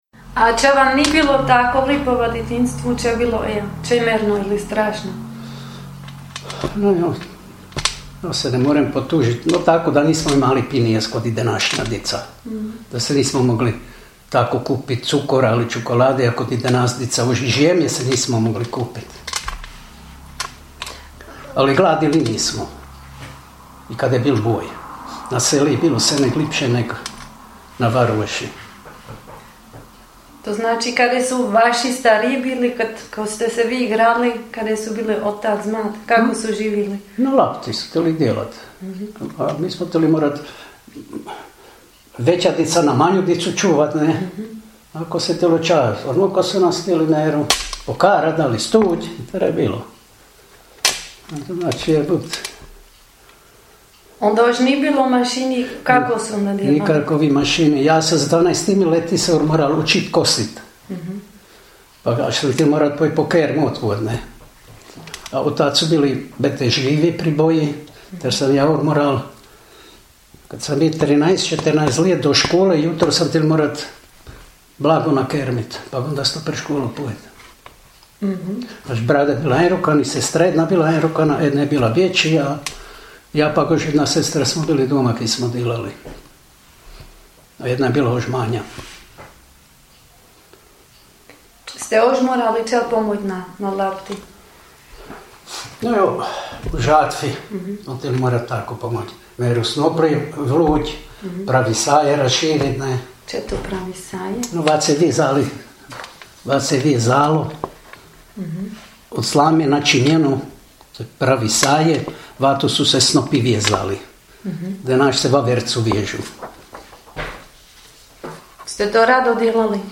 jezik naš, jezik naš gh dijalekti
Filež – Govor